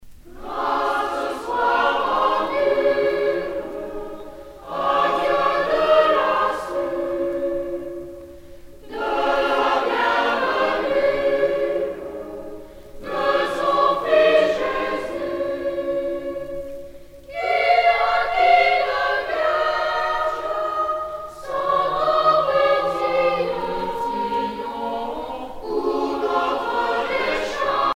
circonstance : Noël, Nativité
Genre strophique